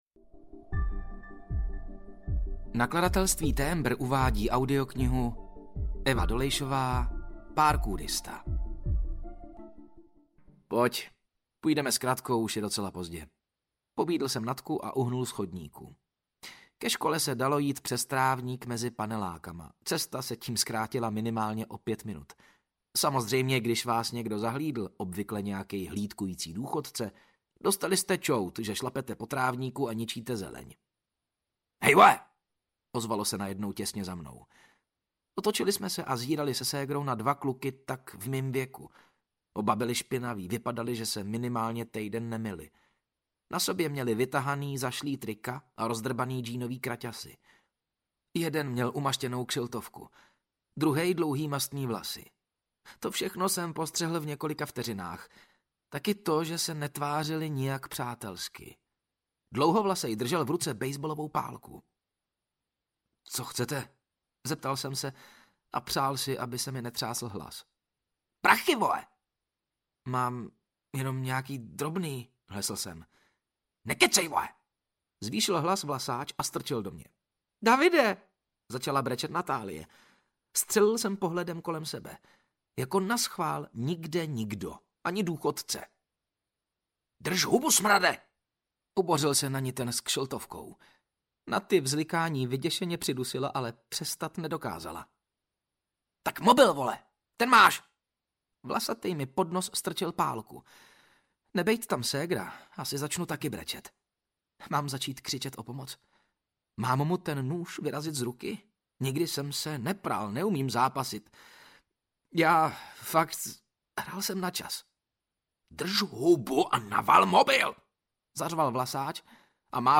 Ukázka z knihy
• InterpretMatouš Ruml
parkourista-audiokniha